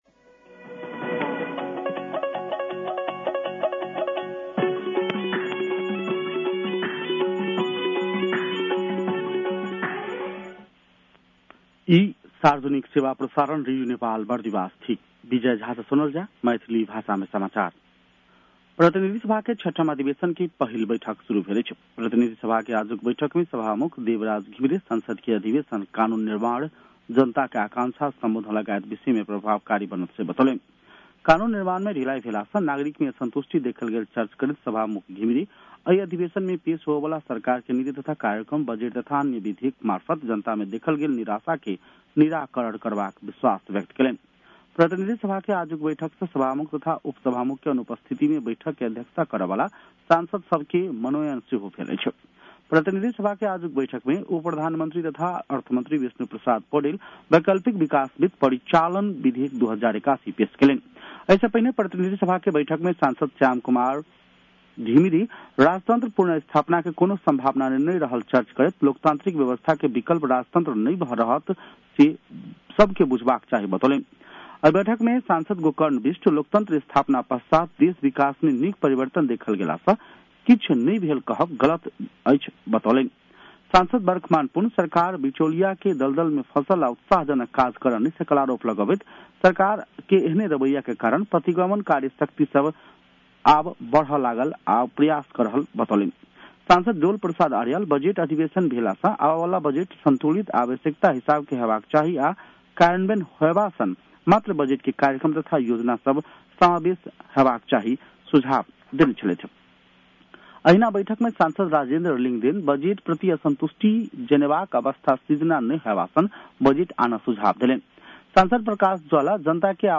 मैथिली भाषामा समाचार : १२ वैशाख , २०८२